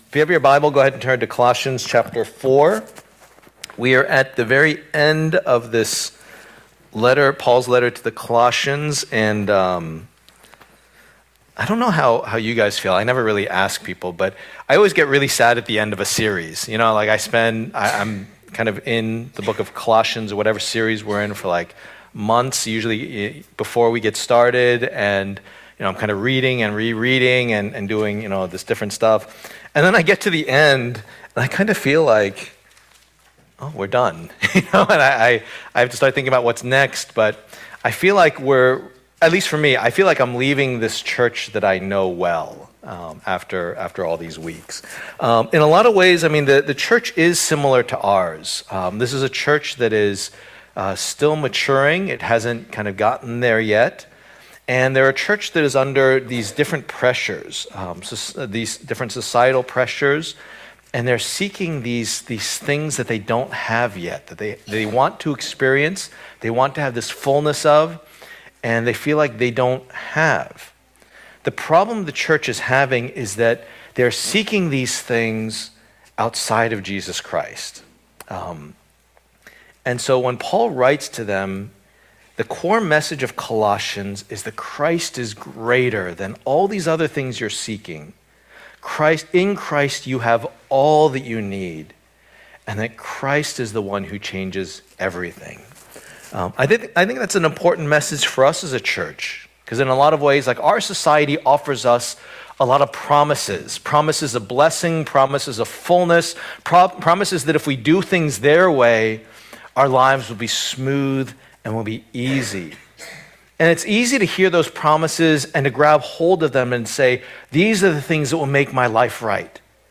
Passage: Colossians 4:7-18 Service Type: Lord's Day